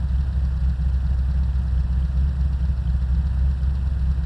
V8_04_idle.wav